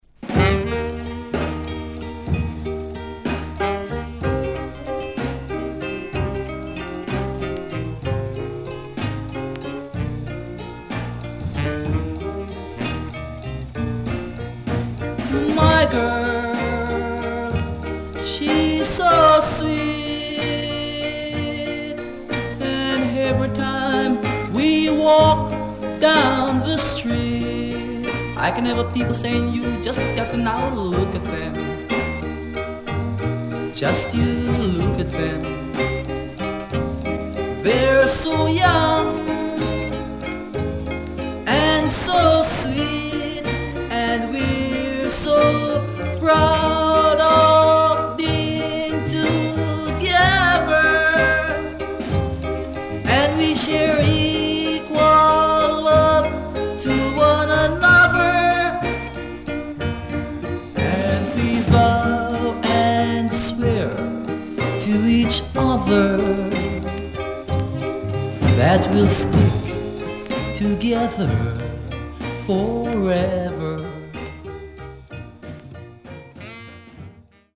Nice Ska Inst & Ballad.W-Side Good
SIDE A:出だしスプレーノイズ、プチノイズ入りますが他良好です。